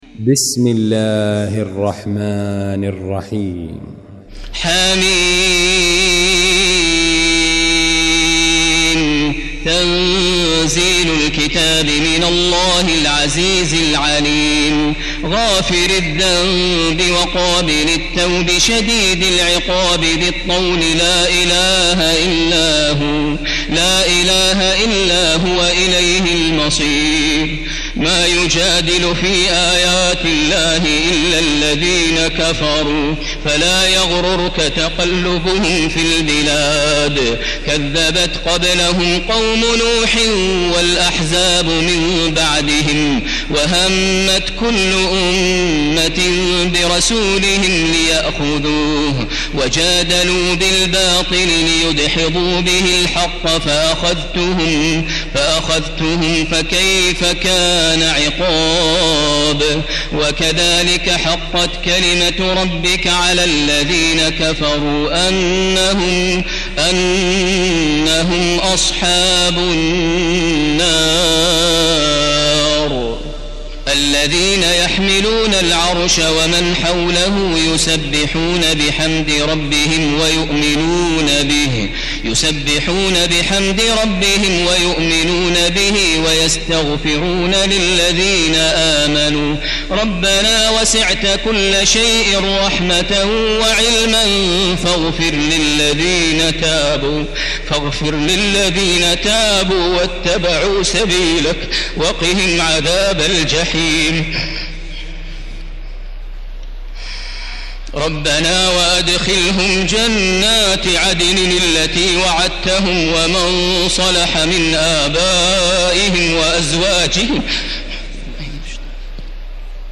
المكان: المسجد الحرام الشيخ: فضيلة الشيخ عبدالله الجهني فضيلة الشيخ عبدالله الجهني فضيلة الشيخ ماهر المعيقلي غافر